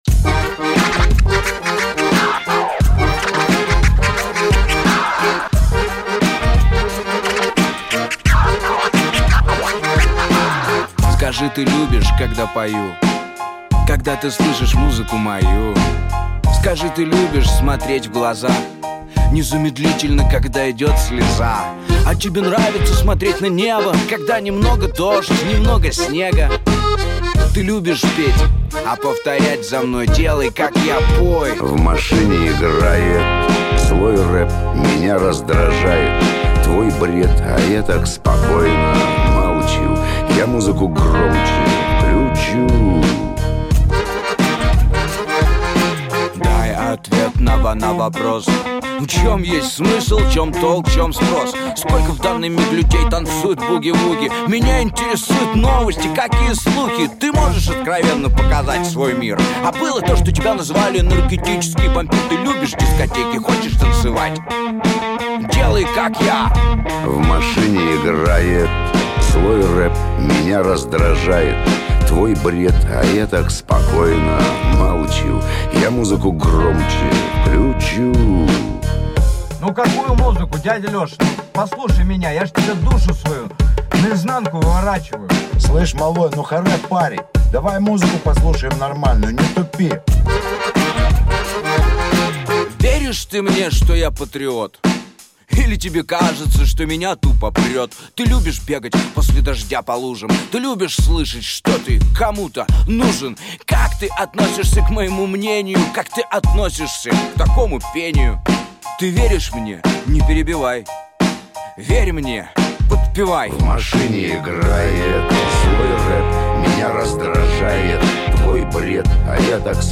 zloyrap(scratch).mp3